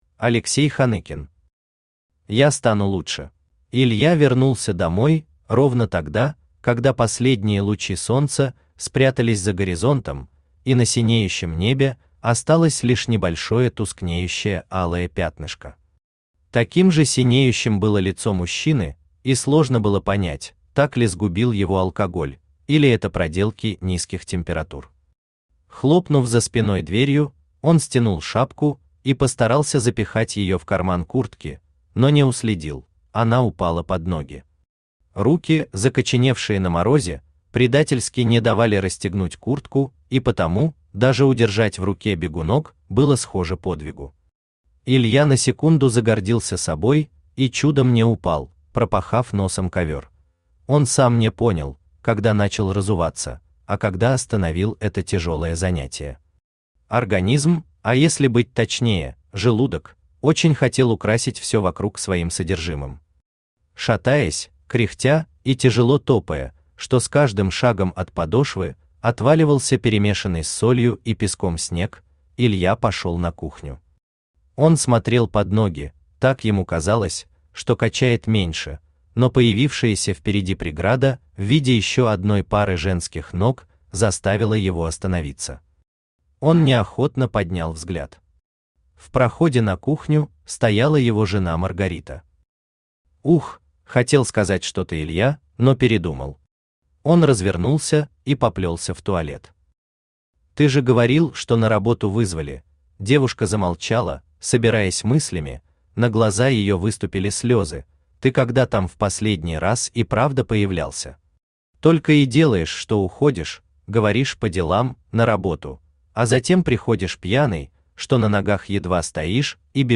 Aудиокнига Я стану лучше Автор Алексей Юрьевич Ханыкин Читает аудиокнигу Авточтец ЛитРес.